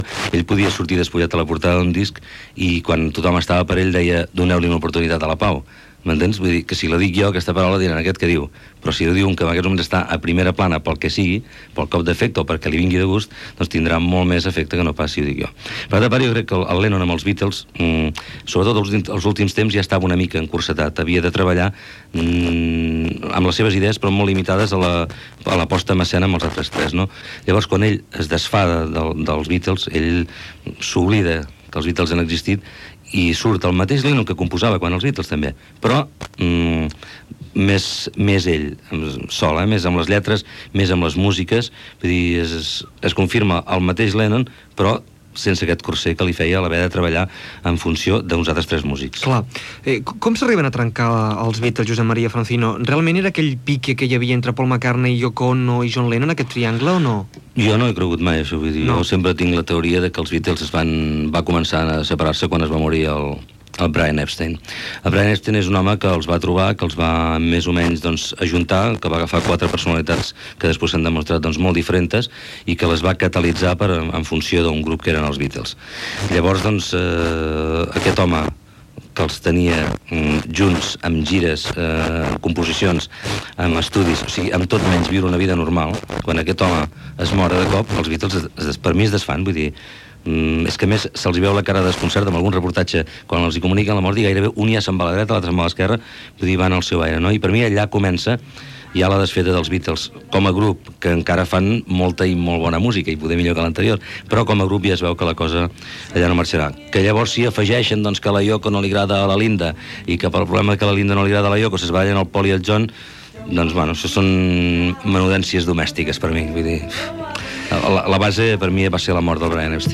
59f20ba2630e5514a5f9401c75067eb56e290fc8.mp3 Títol Ràdio 4 Emissora Ràdio 4 Cadena RNE Titularitat Pública estatal Nom programa Tarda cinc estrelles Descripció Comentaris sobre el músic John Lennon i anunci d'un programa especial que farà Ràdio 4 sobre ell el següent cap de setmana. Publicitat, indicatiu de l'emissora, informació des de Londres de l'activitat cultural Gènere radiofònic Entreteniment